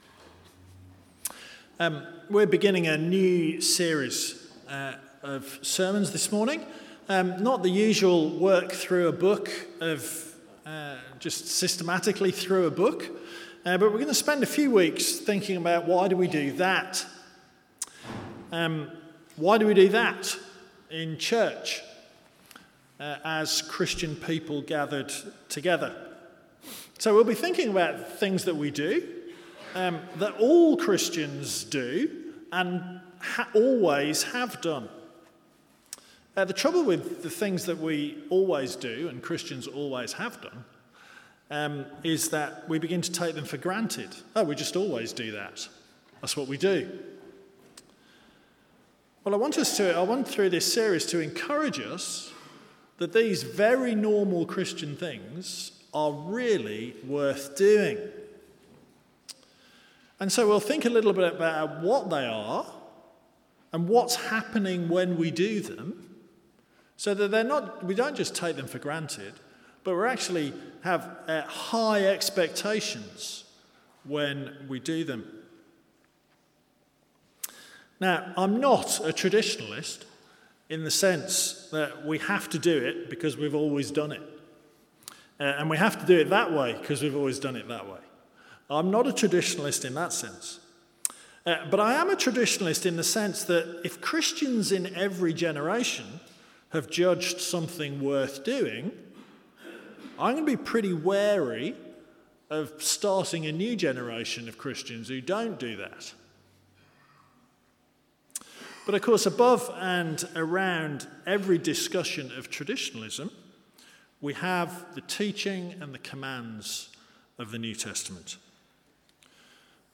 Sermons from Holy Trinity Church, Oswestry